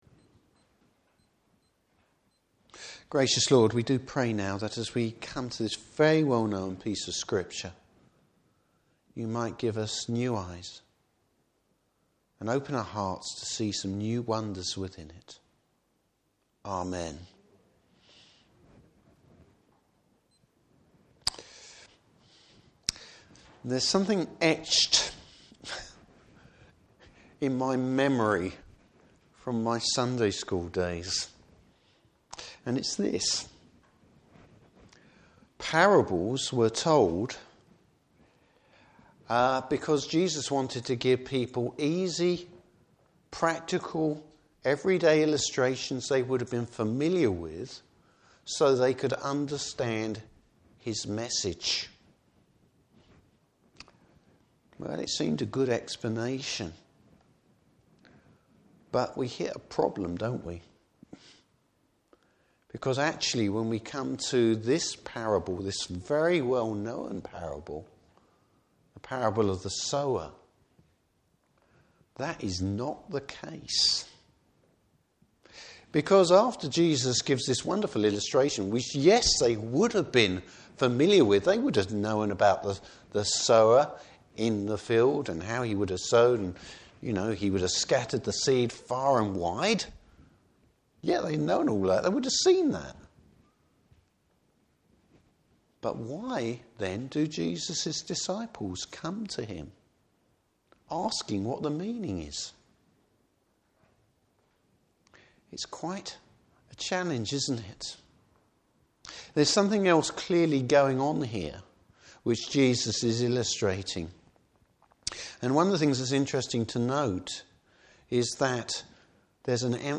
Service Type: Morning Service Bible Text: Luke 8:4-21.